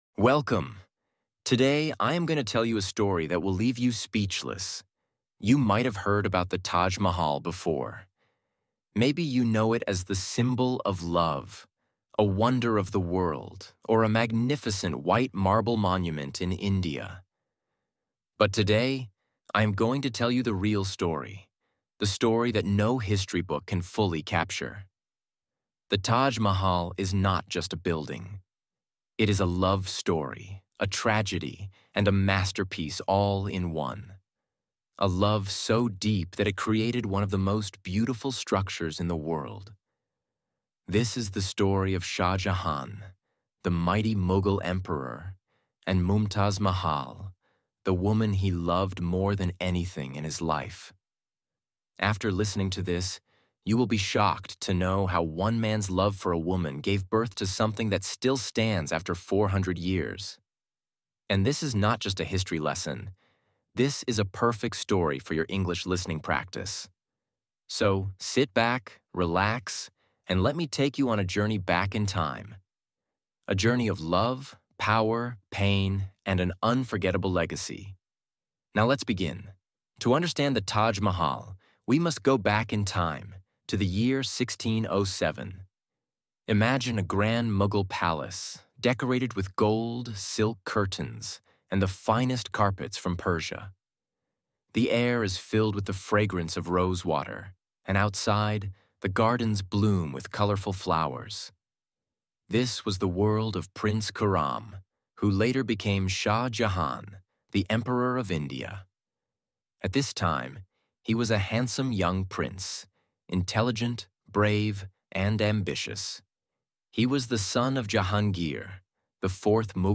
The Taj Mahal’s Hidden History Told in Clear, Powerful English